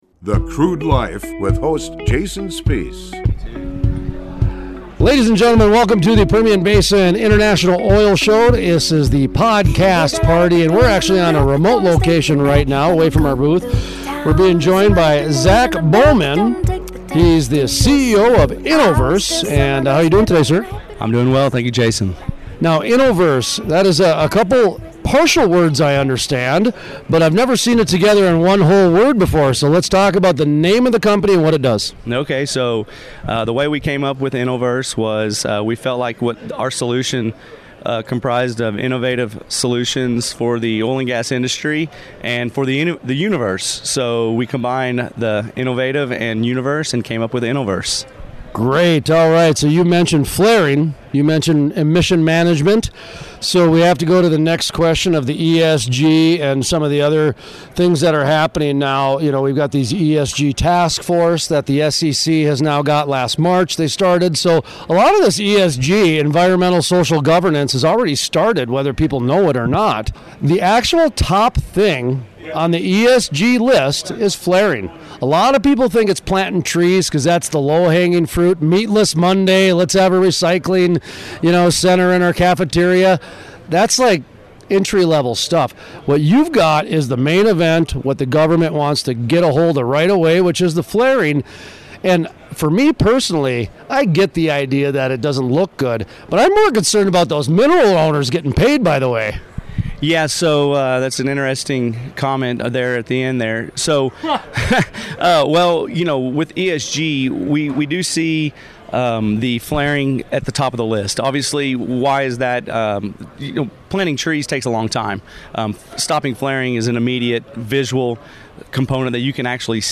at the PBIOS Podcast Party sponsored by The Wireline Group at the Permian Basin International Oil Show (PBIOS) Oil Show in Odessa, Texas.